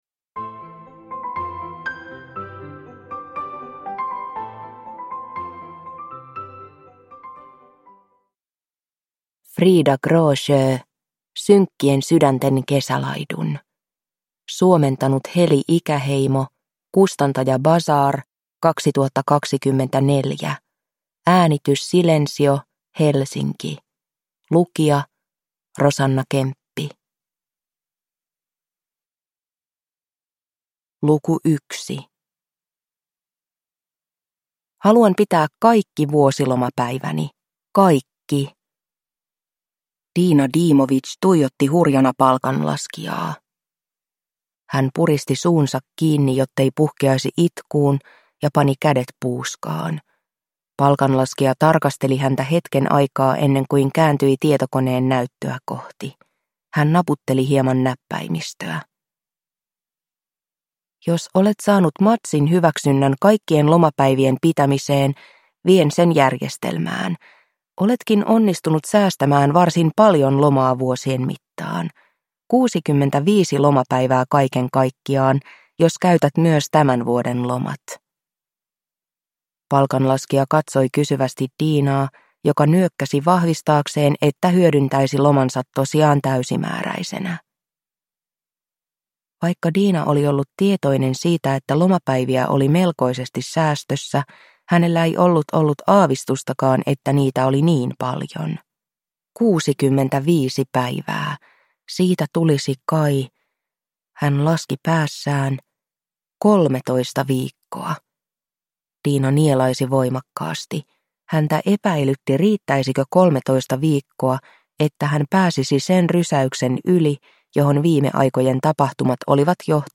Synkkien sydänten kesälaidun (ljudbok) av Frida Gråsjö